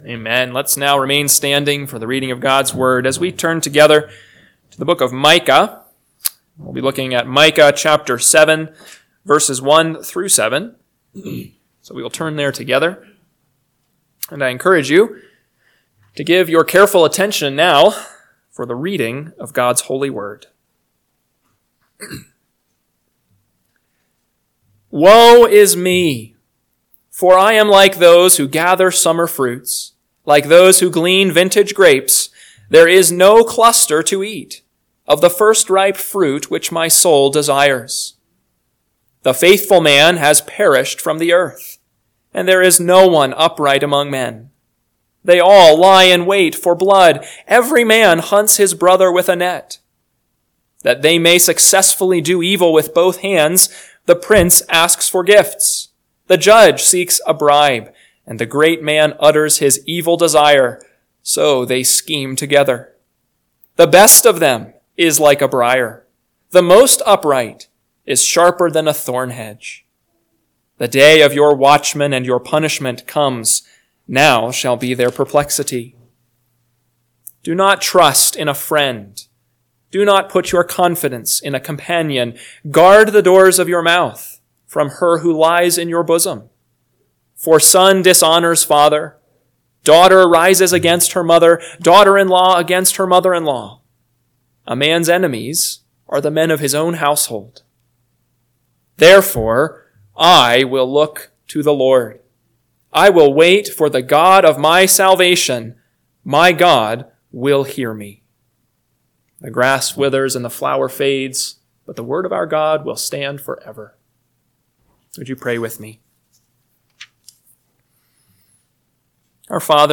PM Sermon – 12/8/2024 – Micah 7:1-7 – Northwoods Sermons